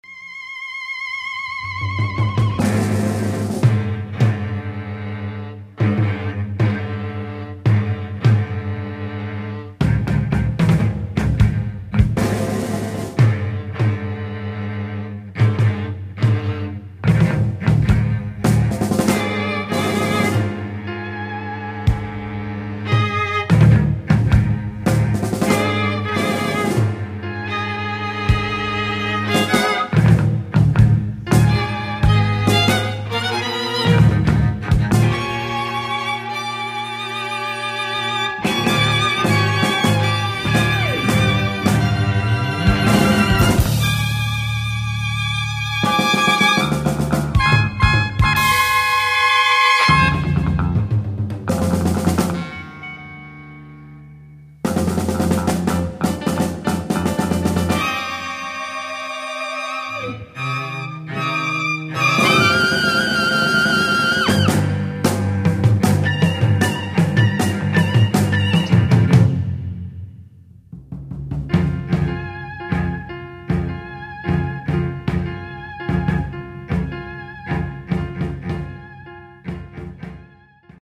Avant-rock septet
(full ensemble)